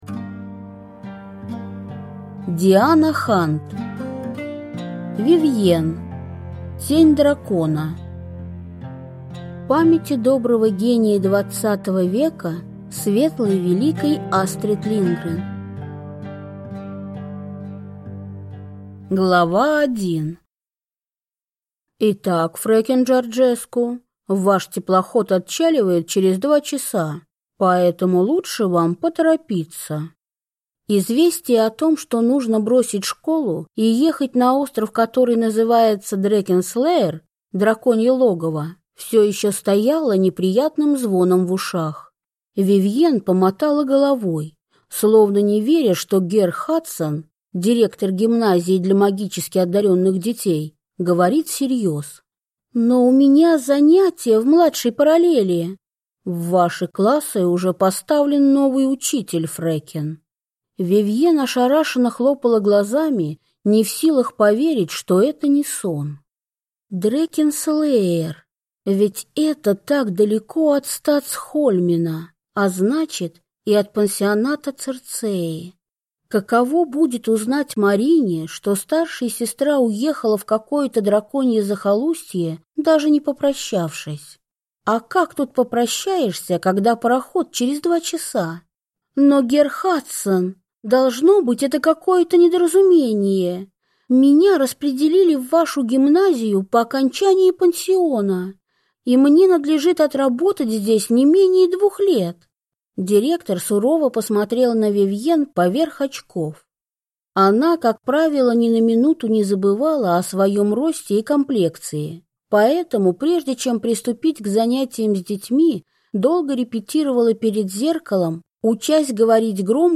Аудиокнига Вивьен. Тень дракона | Библиотека аудиокниг